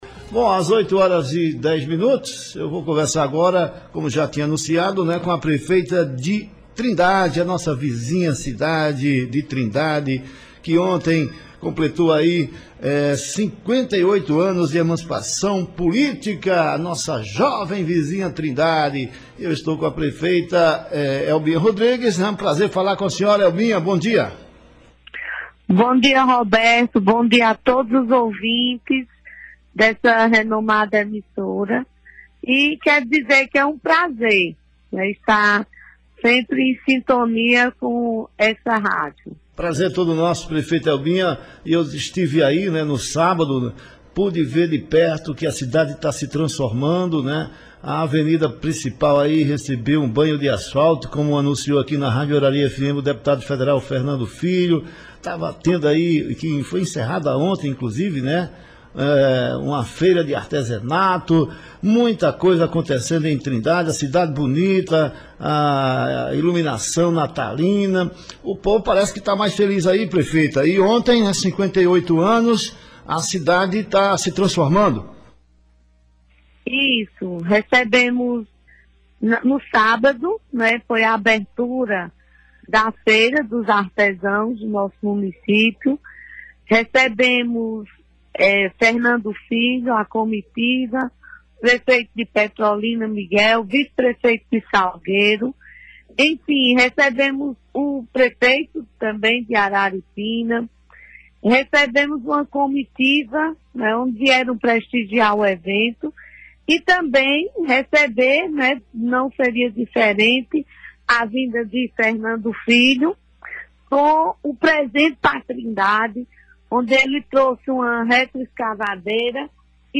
Prefeita disse na Arari FM, ser grata ao deputado federal Fernando Filho por trazer emendas e recursos da Codevasf; ouça entrevista
A prefeita de Trindade, no Sertão do Araripe, Helbinha Rodriges, fez um rápido balanço do seu primeiro ano de gestão nessa terça-feira (21), na Rádio Arari FM. Ela revelou que ainda existem muitas dificuldades, mas aos poucos o município está entrando nos ‘eixos’, principalmente após a parceria feita com o deputado federal Fernando Filho (DEM).